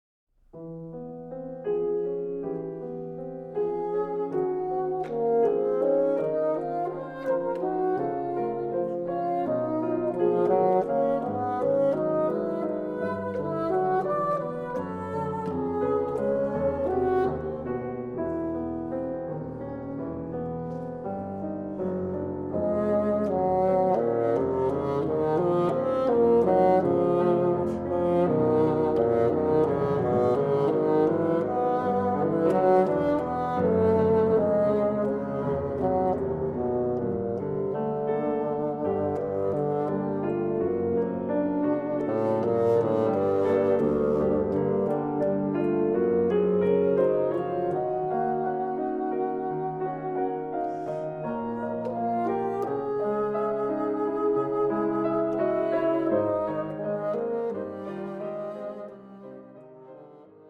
Quartet for Bassoon, Violin, Cello and Piano